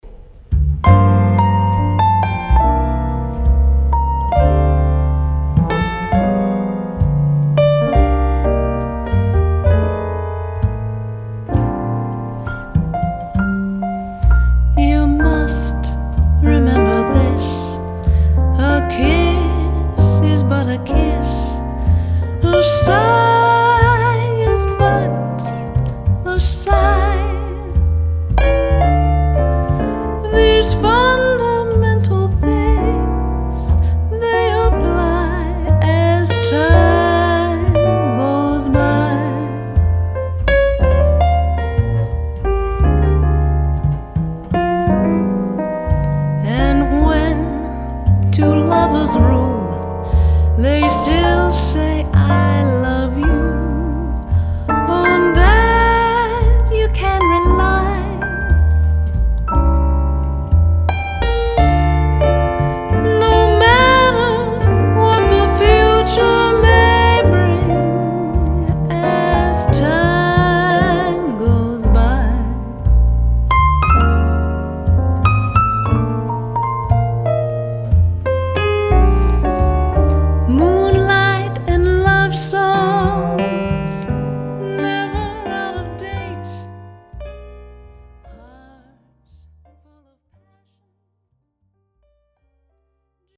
piano
bass.